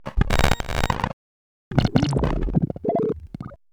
Звуки глитч-эффектов
Звук глитча для монтажа